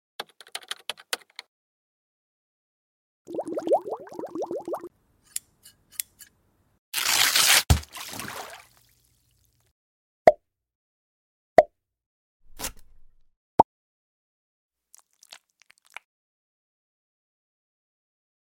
✨ sonidos aesthetic en Canva sound effects free download